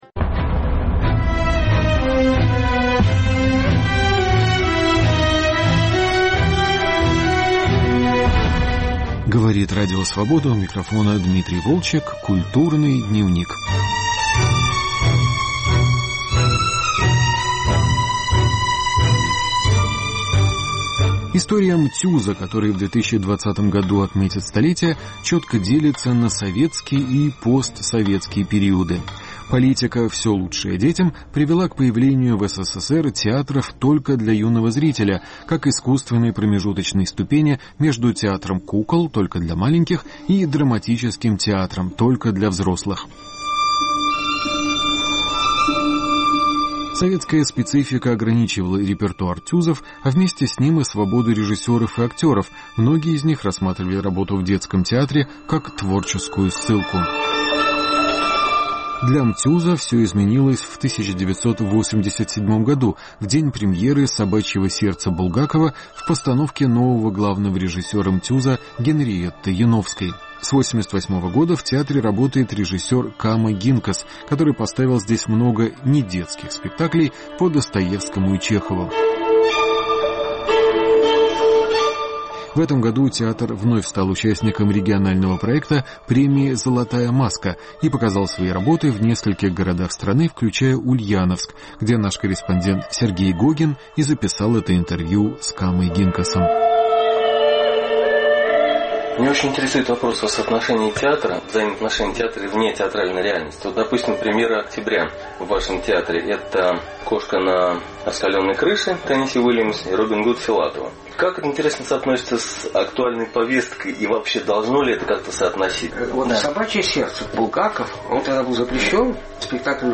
Разговор с режиссером Камой Гинкасом